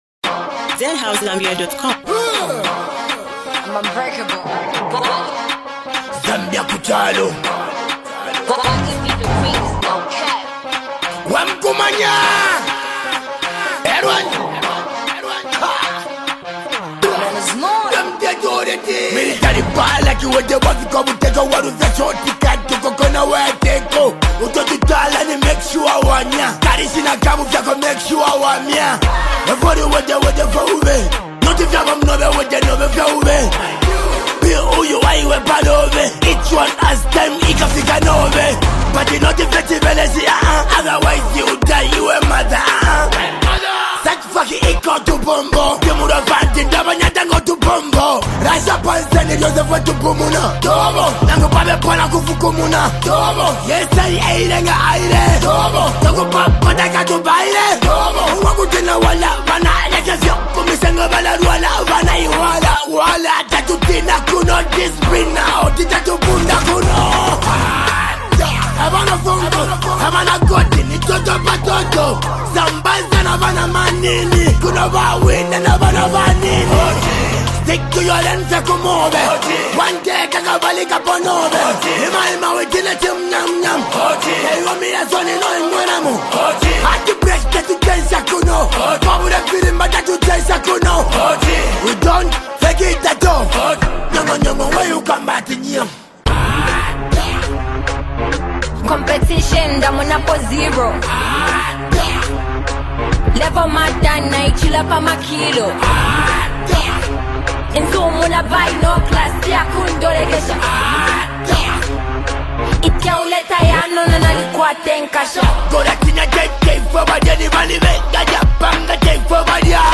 powerful street banger
A must-listen collabo with a catchy hook and strong message.